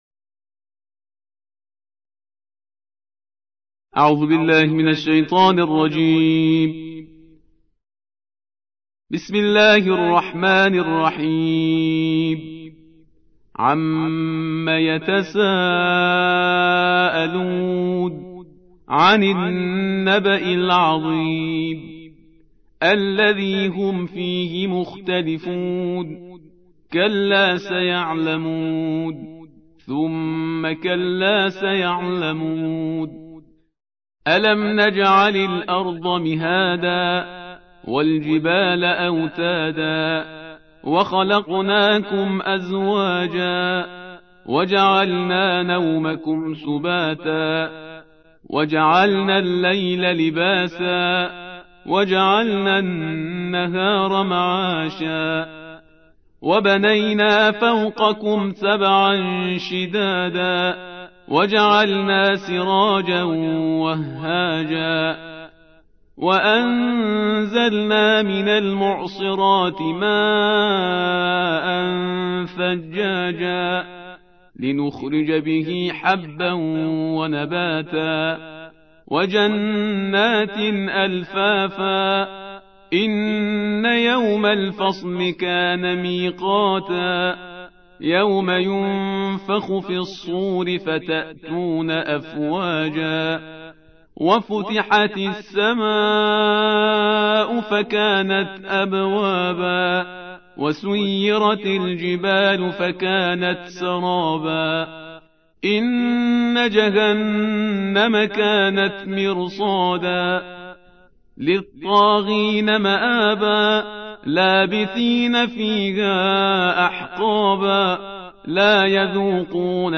تحميل : الجزء الثلاثون / القارئ شهريار برهيزكار / القرآن الكريم / موقع يا حسين